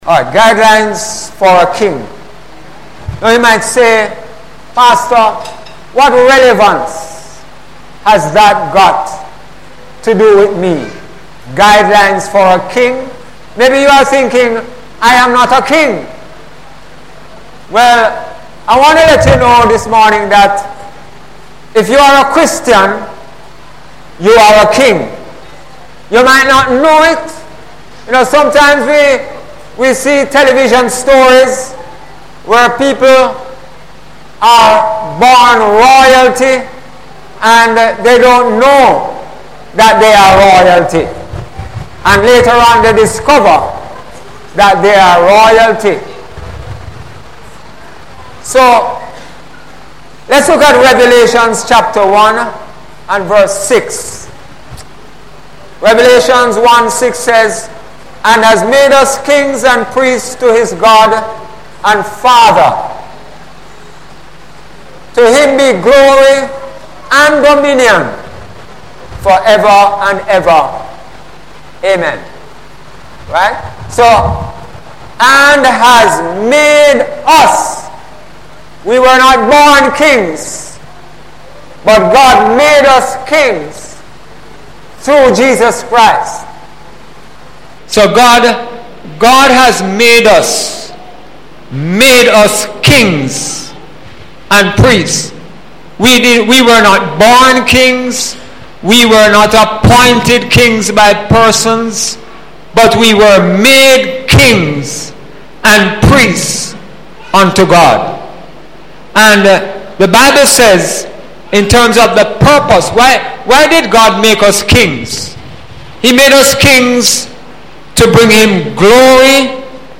Sunday Sermon – April 2, 2017 – Being Kings and Priests For God